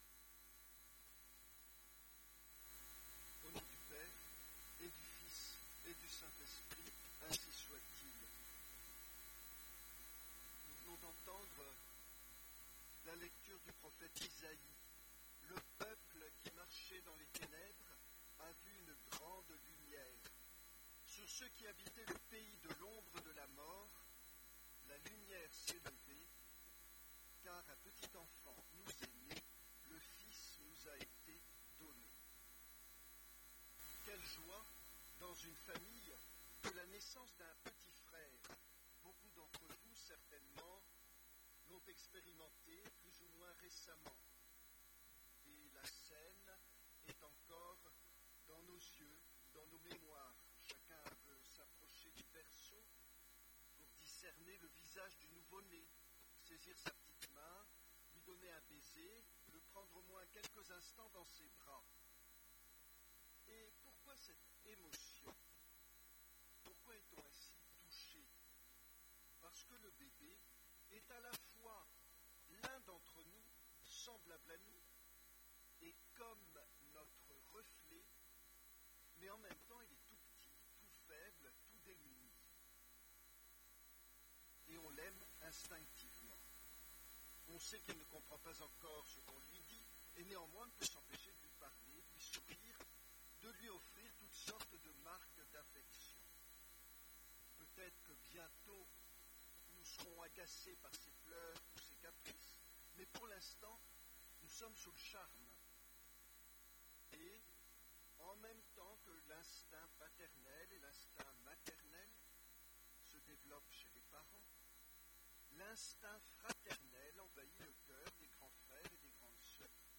Occasion: Fête de Noël — Messe de minuit
Type: Sermons